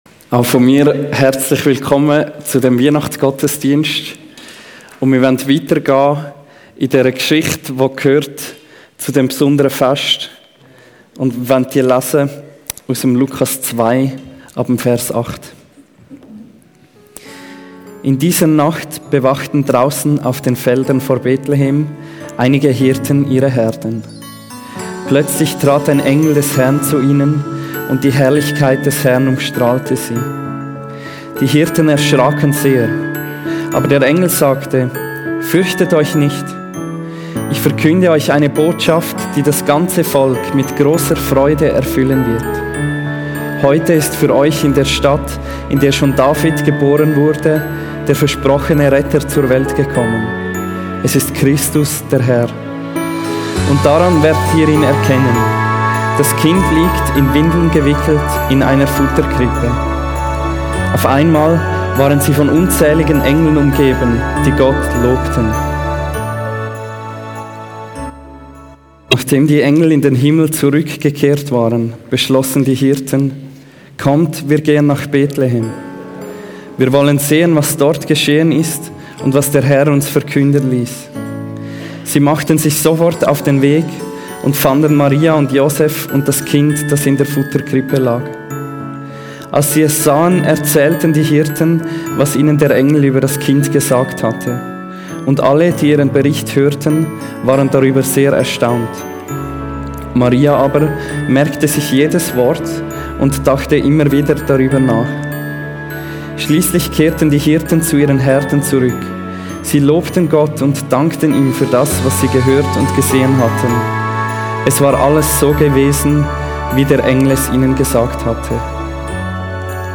Online Predigt